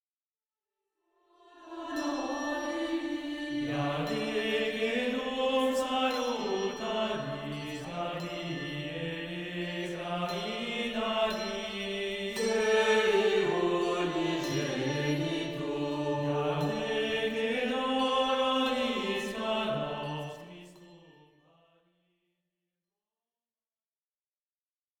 Prose mariale